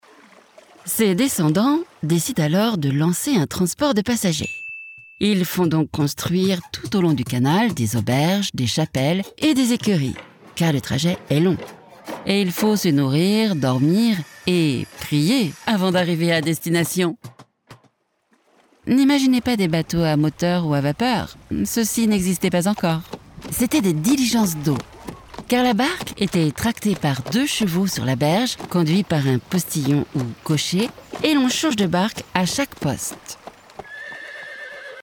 Minha voz é calorosa, feminina e adequada para narração, elearning, audiolivros, audioguias, mas também alguns comerciais, jogos.
Micro Neumann 103
Uma cabine Isovox em uma cabine à prova de som